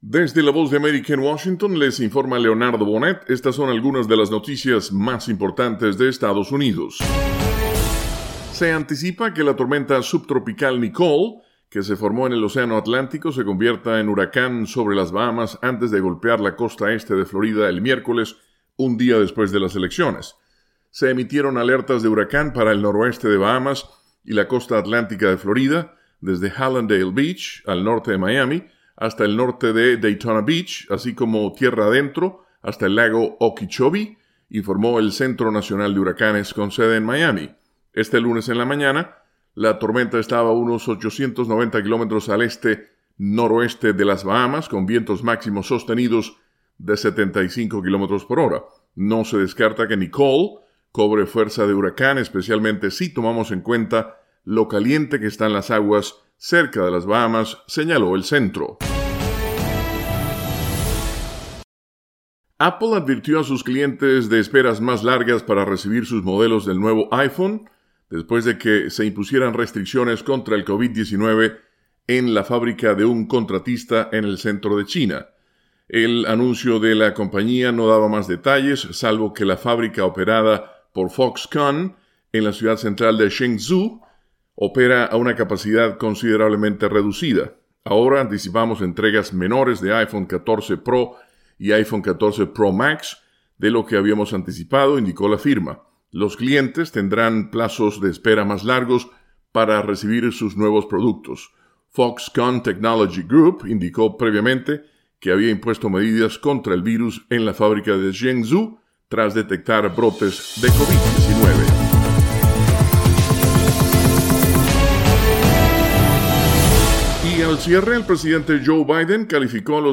Resumen con algunas de las noticias más importantes de Estados Unidos